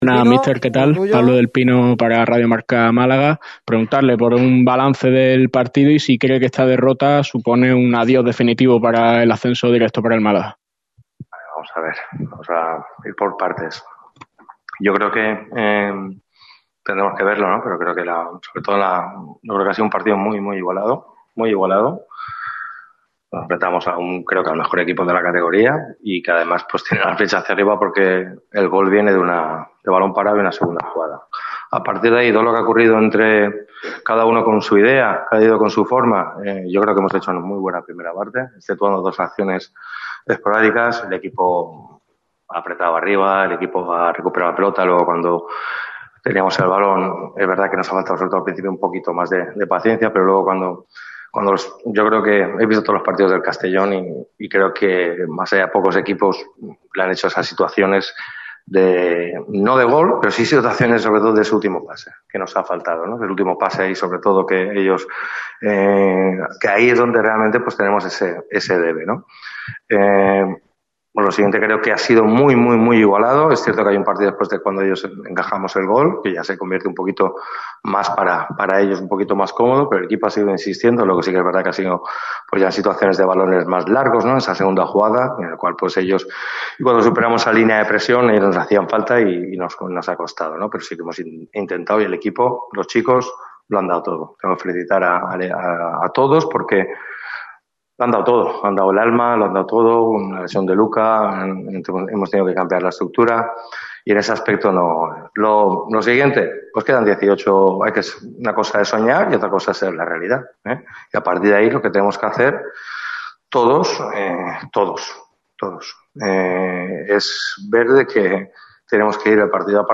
El técnico de Nules compareció en rueda de prensa después de la derrota ante el CD Castellón que hace que el Málaga CF diga prácticamente adiós a las opciones del ascenso directo. Pellicer se mostró contento con el esfuerzo de sus jugadores, pero enfadado por el resultado.
Estas han sido las declaraciones del preparador malaguista en rueda de prensa.